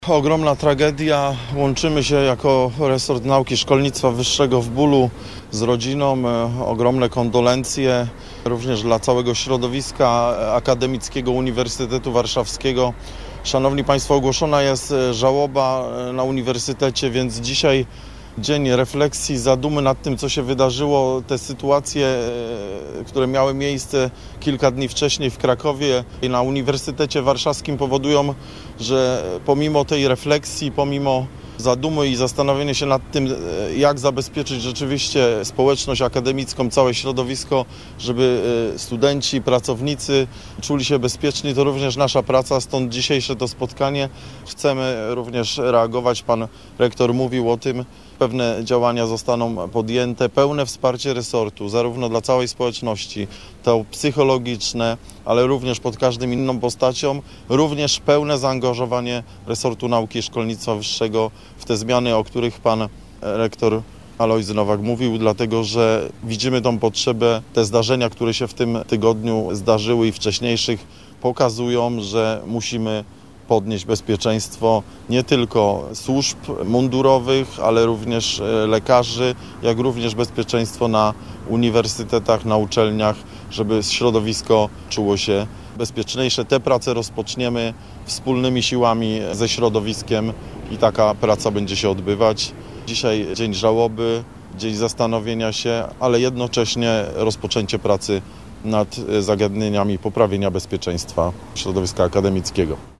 Ze studentami i pracownikami Uniwersytetu Warszawskiego w czwartek spotkał się minister nauki i szkolnictwa wyższego.